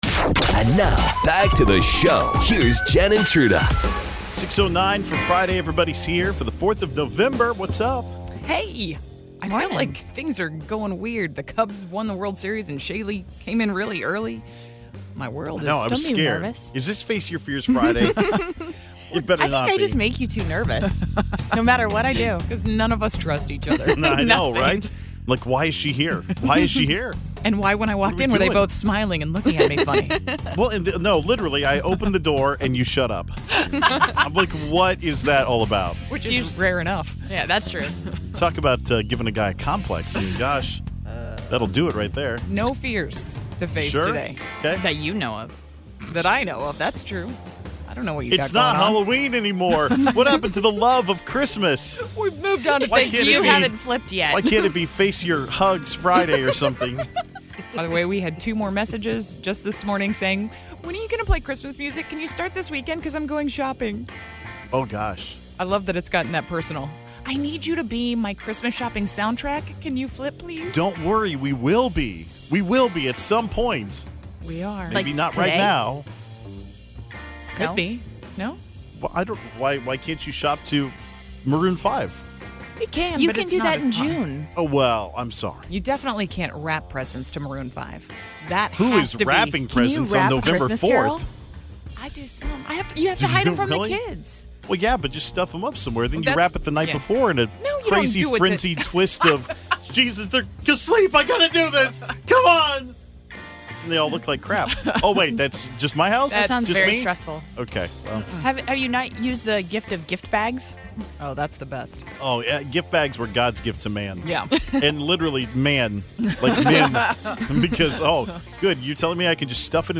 What have you had stolen from you at work? Great listener calls here.